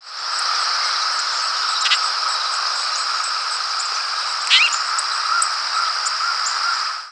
Baltimore Oriole diurnal flight calls
Diurnal calling sequences: